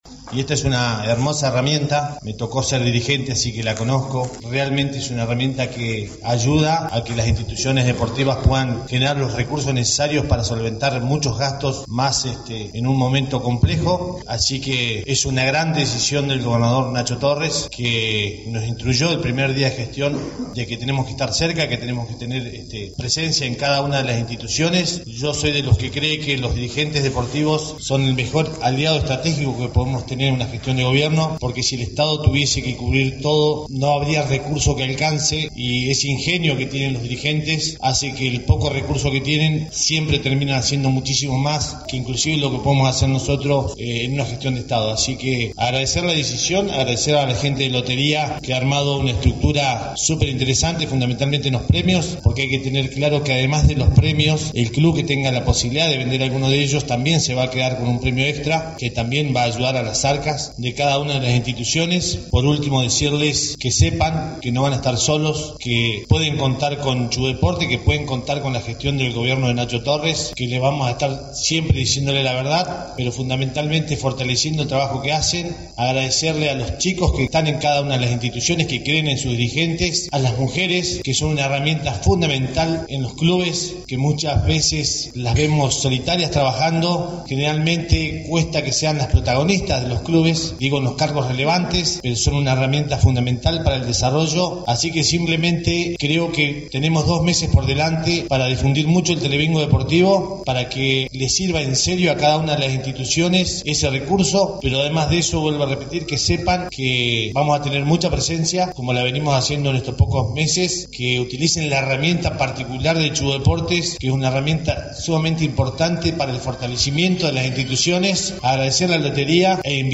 Con la participación del intendente Damián Biss por la Municipalidad de Rawson junto al presidente de Chubut Deportes, Milthon Reyes y la participación del  gerente general de Lotería, Ramiro Ibarra, se llevo a cabo en la capital provincial, la noche del lunes 29 de abril, la presentación del Telebingo Deportivo que se sorteara en aproximadamente sesenta días aproximadamente.
En segundo término el presidente de Chubut Deportes destaco el compromiso de las entidades deportivas a través de sus deportistas y dirigentes que aprovechan dicha herramienta para obtener fondos, Este es su testimonio;